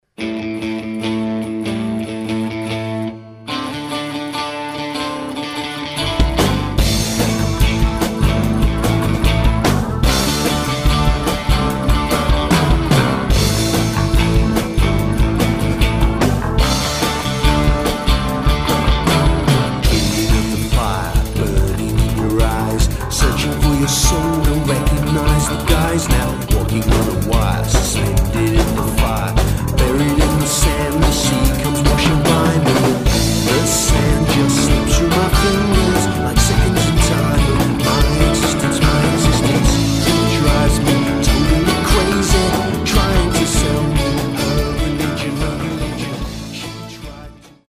The song is driven by insistent throbbing bass
unusual with its middle east rhythms and tones merged with
western rock and pop music.